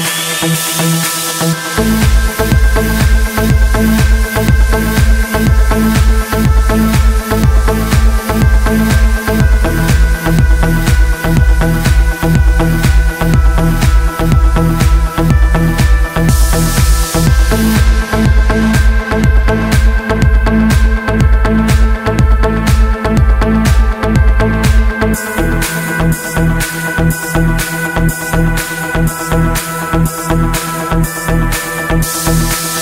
без слов
красивая мелодия
deep progressive
Стиль: progressive house